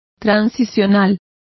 Complete with pronunciation of the translation of transitional.